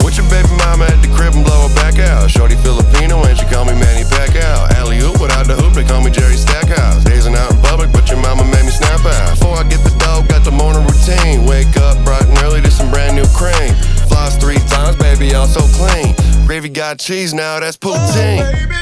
alarm5.wav